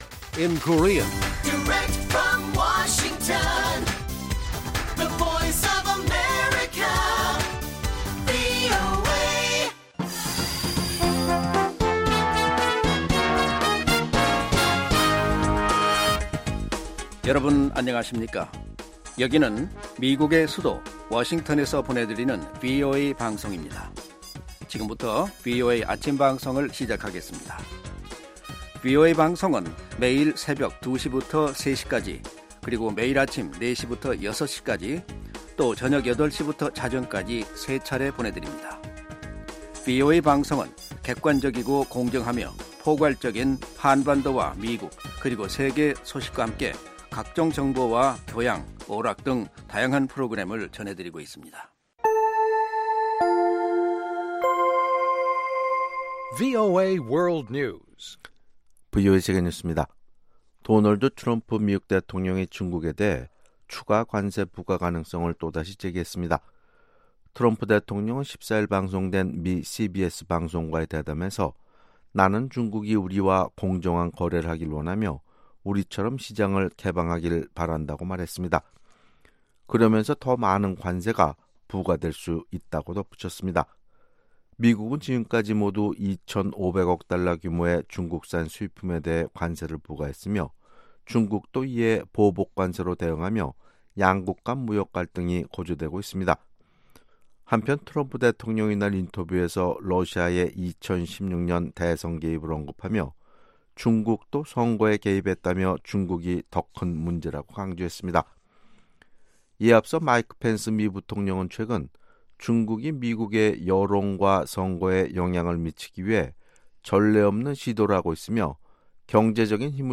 생방송 여기는 워싱턴입니다 10/16 아침